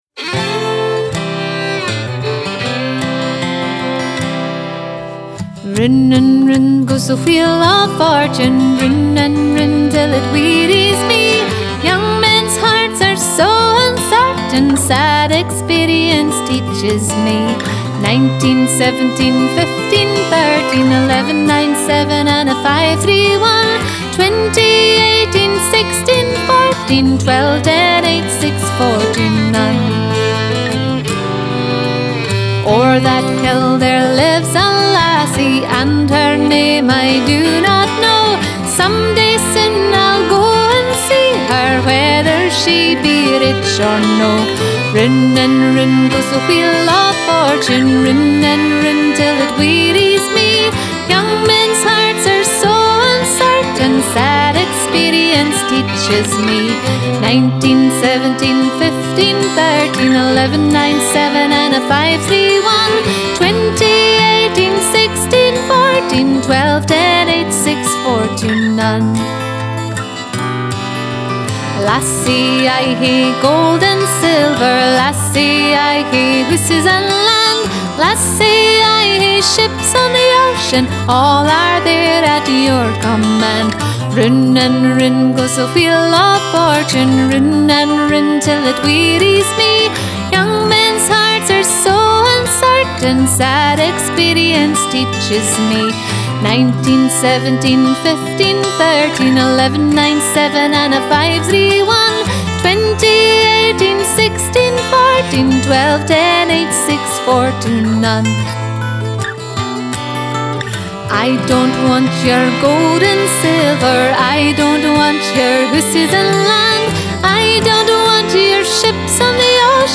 Scottish Music Download The Wheel of Fortune MP3